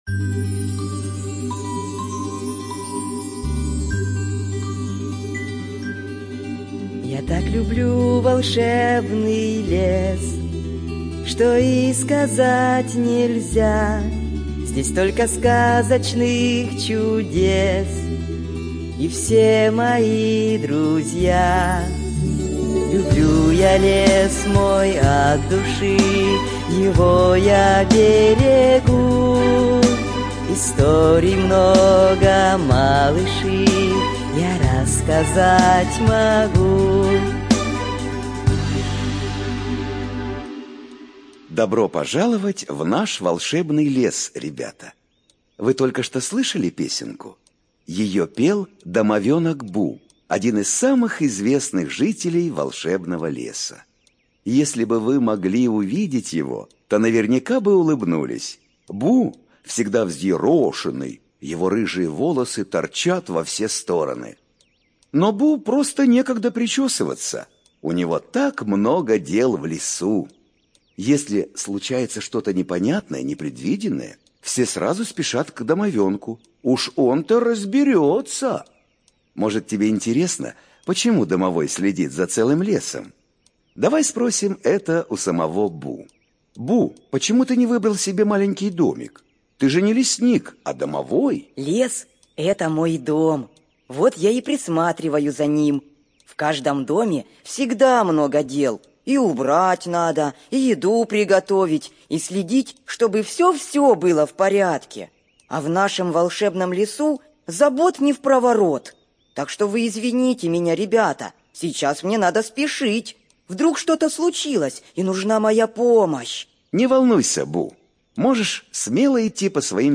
ЖанрДетская литература, Наука и образование
Студия звукозаписиИДДК